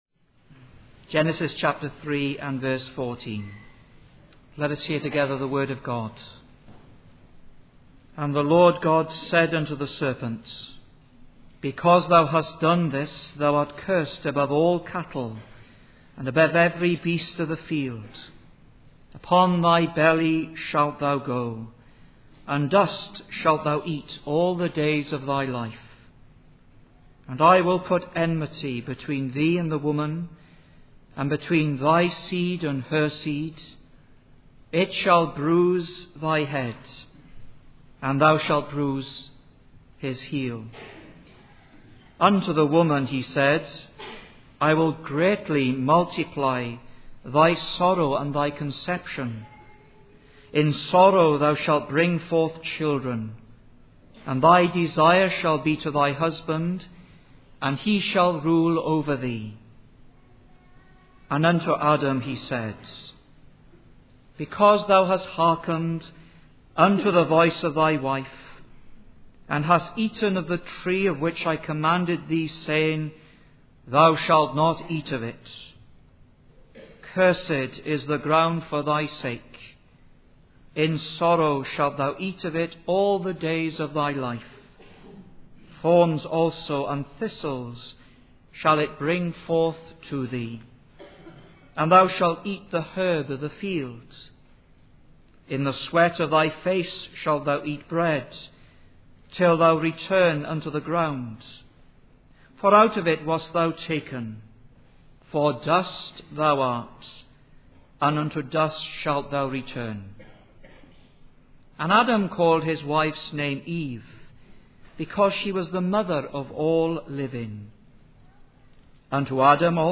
The sermon serves as a reminder of the importance of faith in the believer's relationship with God and the assurance of His promises.